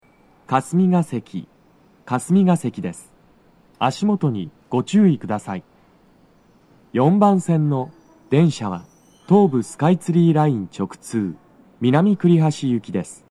足元注意喚起放送が付帯されており、粘りが必要です。
到着放送3
hkasumigaseki4toucyakuminamikurihashi.mp3